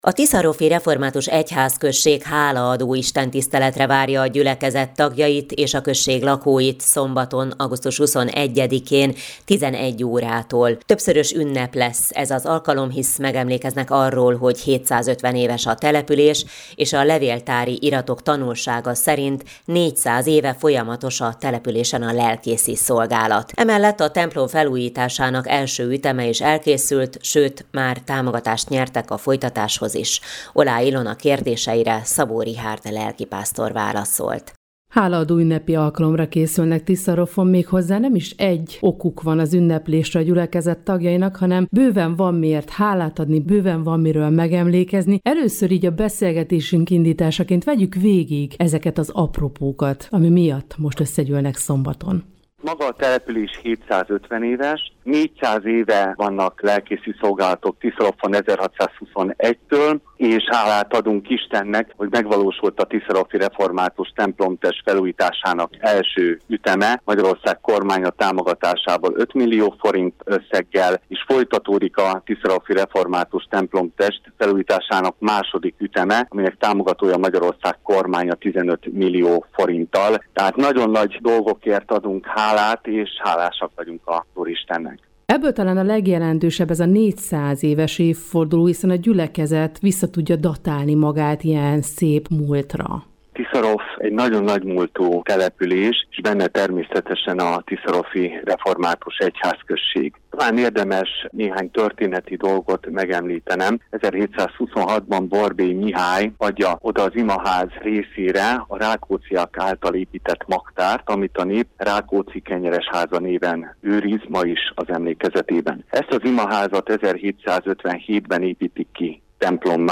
Hálaadó istentisztelet a Tiszaroffi Református Egyházközségben
Az alkalmon igét hirdet dr. Fekete Károly, a Tiszántúli Református Egyházkerület püspöke. Az eseményen hálát adnak a 750 éves Tiszaroff településért, a helyi, 400 éves lelkészi szolgálatért, valamint a felújítási munkálatokért.